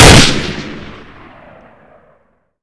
weapons
sg550-1.wav